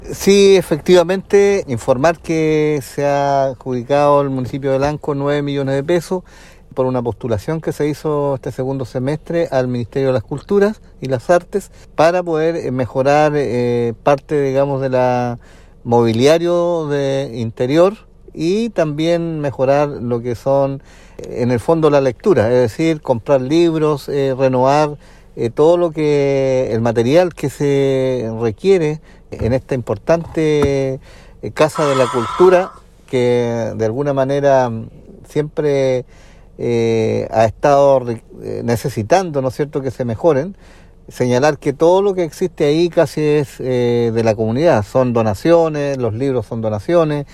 Alcalde-Rocha-Biblioteca-Malalhue.mp3